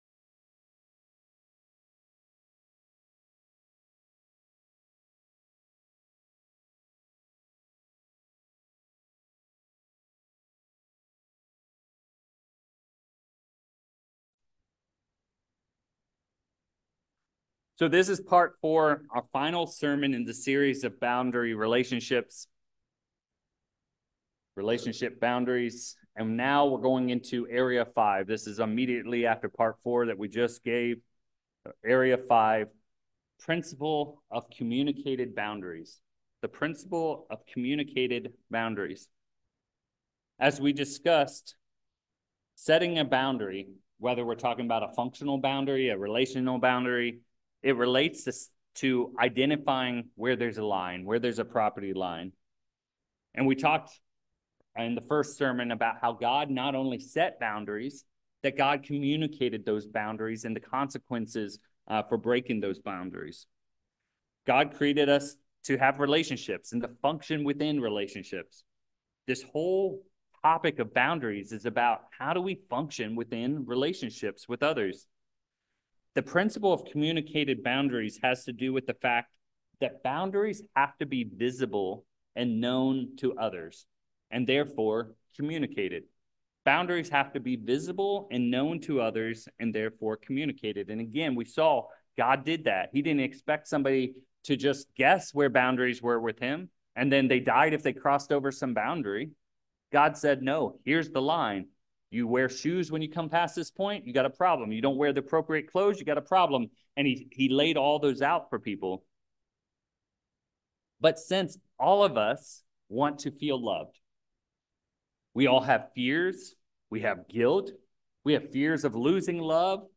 3/9/24 In this fourth sermon of the four-part series, we build off the Biblical foundation we established in parts 1 and 2, and continue with the seminar style of part 3 (not a lot of scriptures) as we bring this sermon series to a close. In this final part, we explore how boundaries must be communicated, and dive into a number of ideas that people can have toward boundaries. We will wrap up the message by looking at the three areas where we need boundaries and the importance of ensuring that consequences for breaking boundaries are enforced.